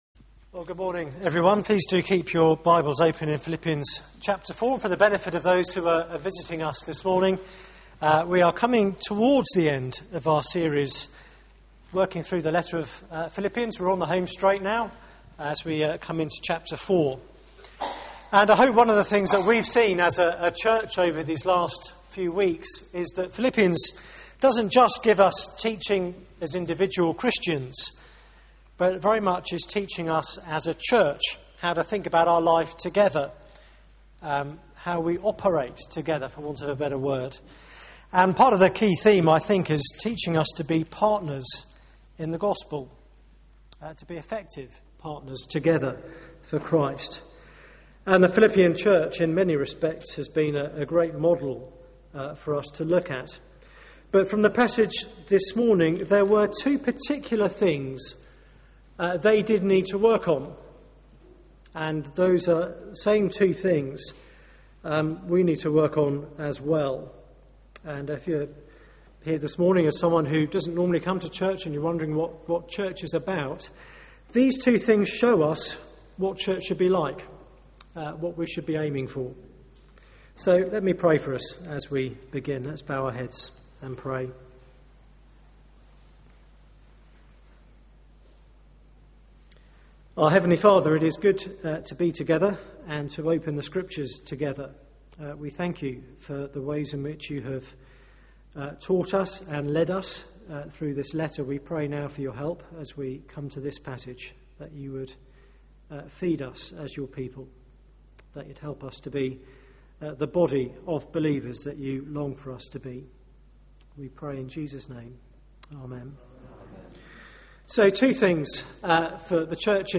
Media for 9:15am Service on Sun 10th Jul 2011 09:15 Speaker
Series: Partners in Christ Theme: Division in the church Sermon